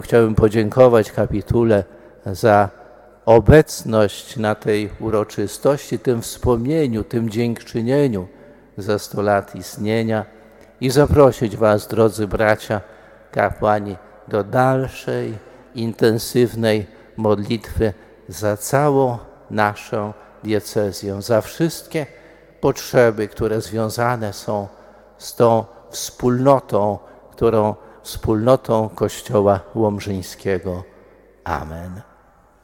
Centralnym punktem obchodów była uroczysta Msza Święta odprawiona, gromadząca biskupów, kapłanów, osoby konsekrowane, wiernych.